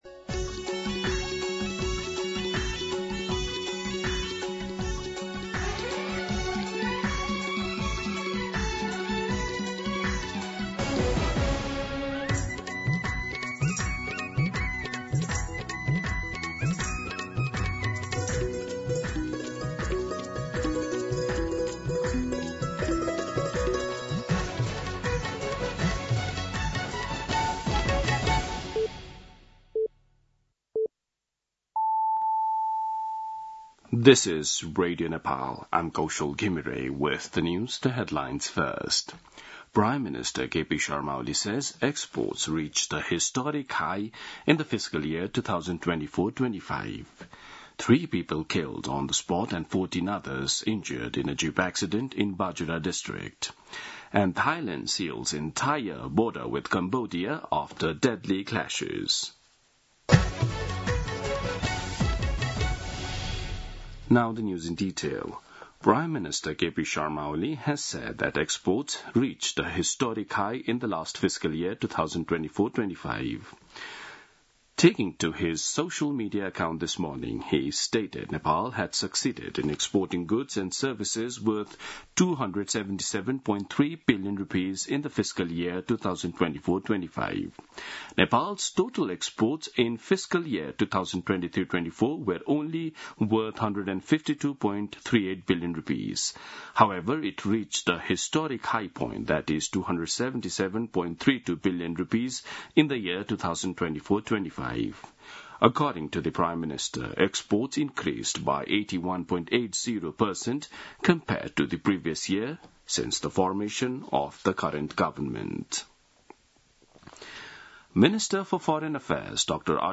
दिउँसो २ बजेको अङ्ग्रेजी समाचार : ८ साउन , २०८२